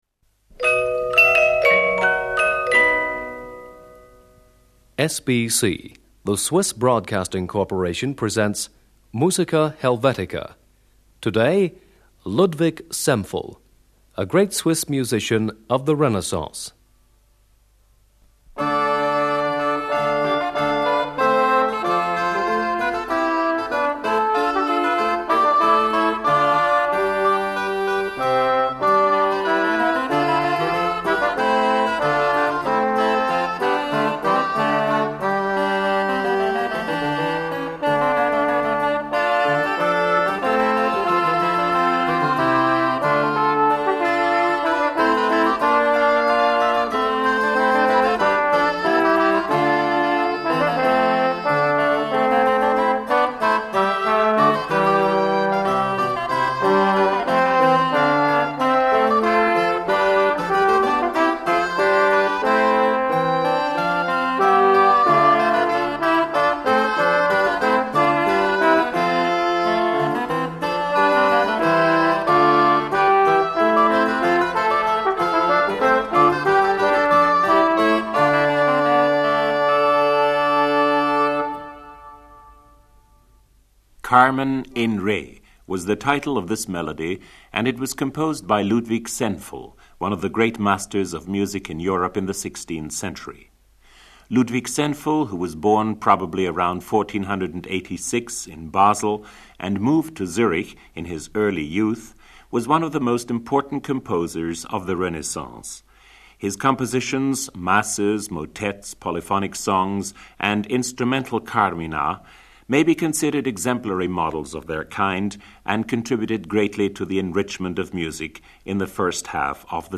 Ludwig Senfl: Carmen in re.Ricercare ensemble for ancient music (Pommer I&II, trombone, dulcian, spinet).
Tenor. Ricercare-Ensemble (soprano-recorder, viol, bass-viol).